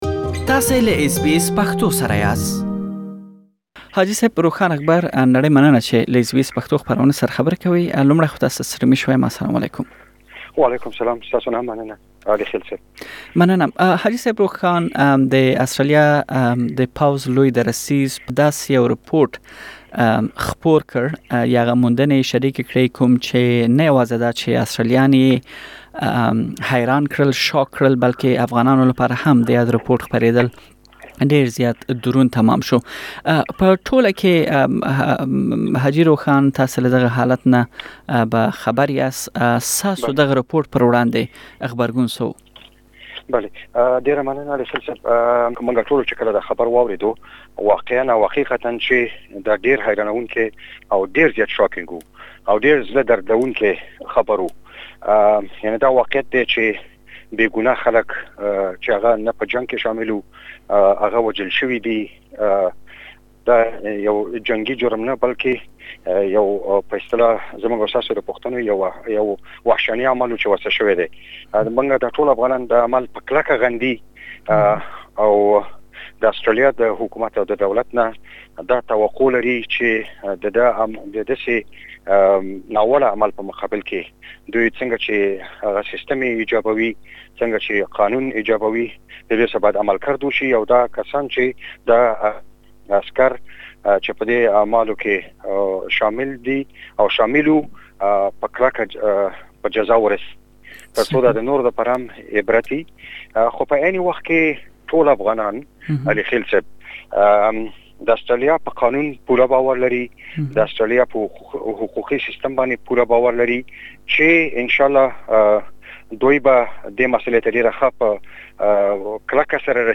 اسټراليا کې د دوو لويو ټولنو استازو له اس بي اس پښتو خپرونې سره خبرو پر مهال د يادو پيښو غندنه وکړه او ترڅنګ يې قربابي شوو کورنيو ته تاوان او ښکيلو پوځيانو ته د سزا ورکولو غوښتنه وکړه.